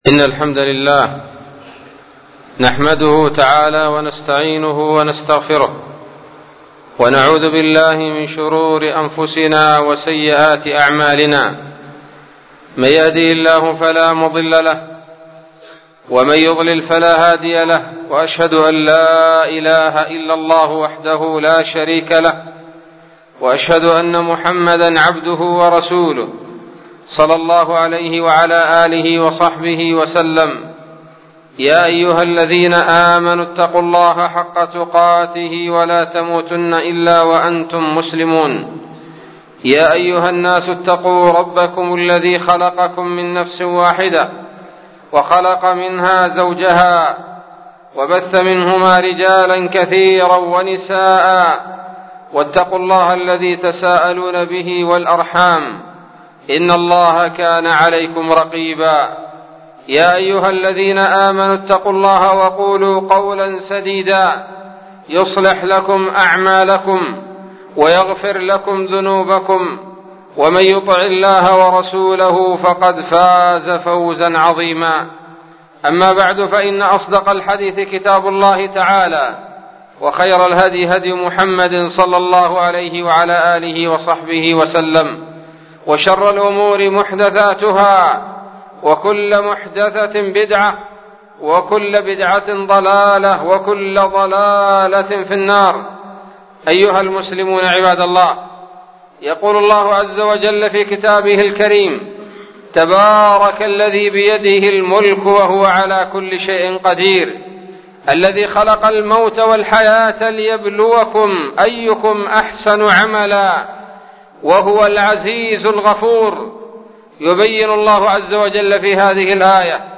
خطبة بعنوان : ((ابتلاء أهل الحق))